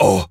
gorilla_hurt_03.wav